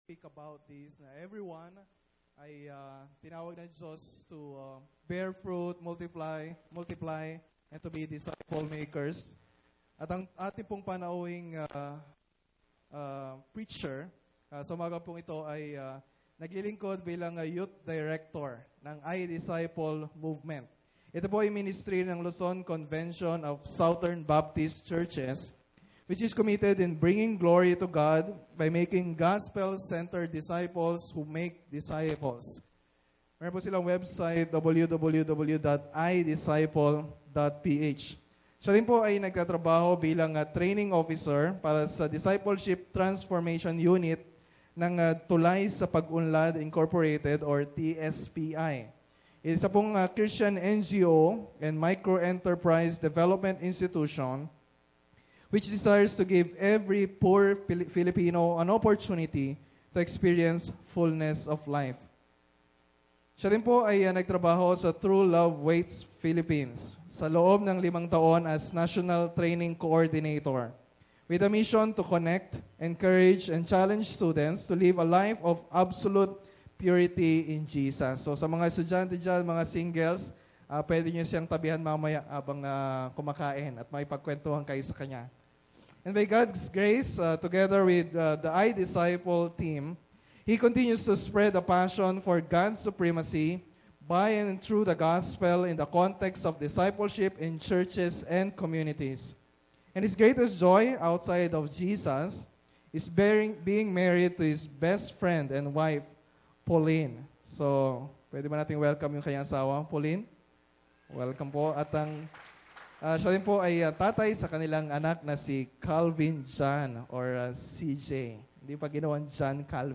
Listen to this sermon (preached on the 28th anniversary of BBCC, Sept. 28, 2014)